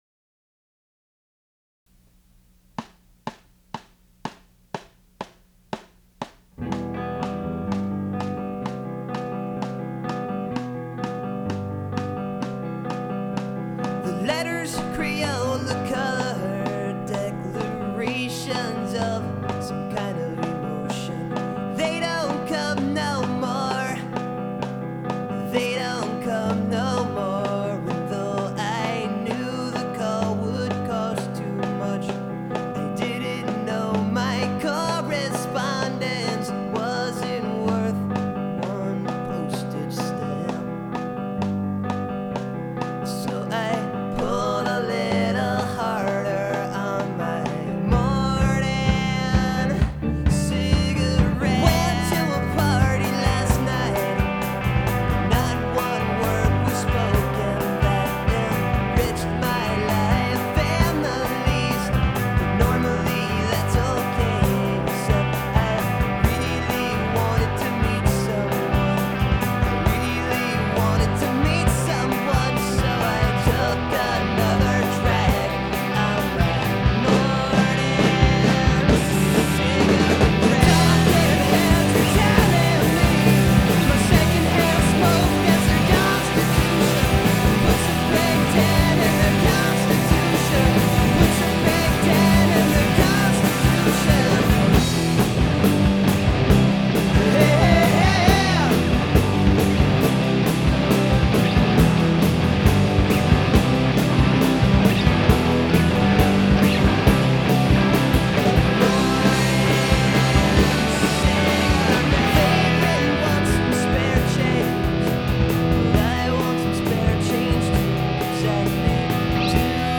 Recorded August 1994 at Dessau, NYC